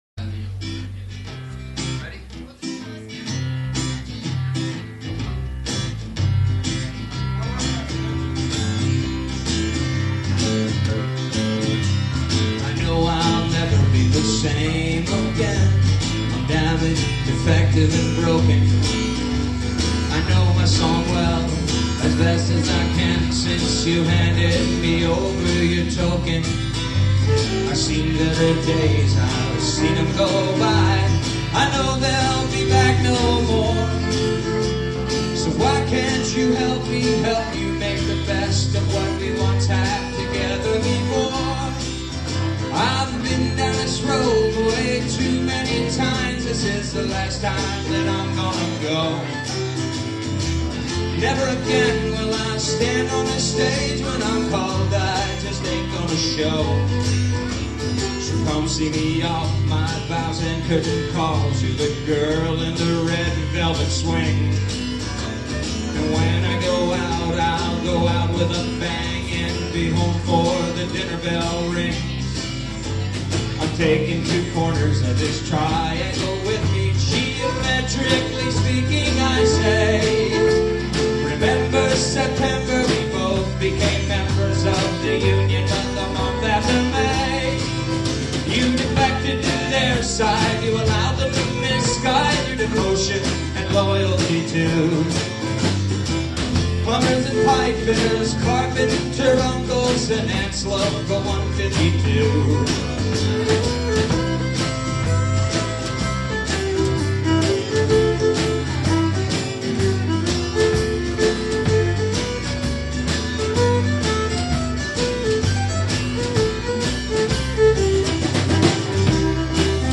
Live at Deja Vu,